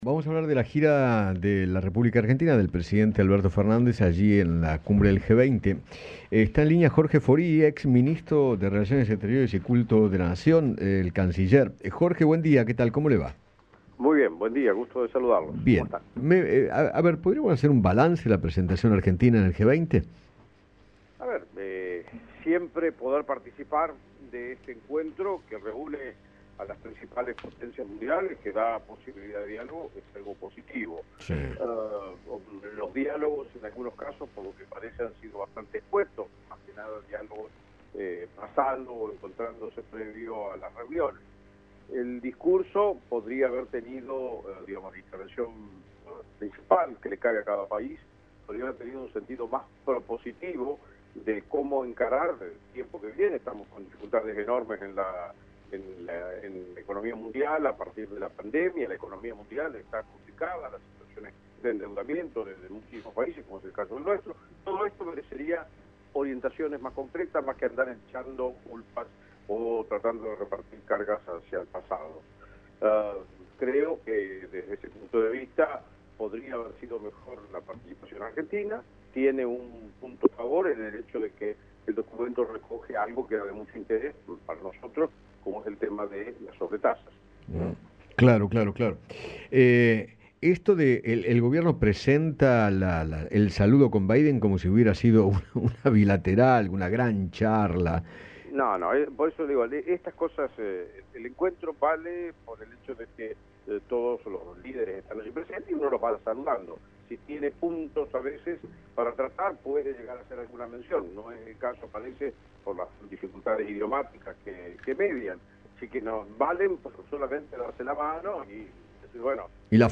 Jorge Faurie, ex canciller, conversó con Eduardo Feinmann sobre la participación de Alberto Fernández en el G20 en Roma.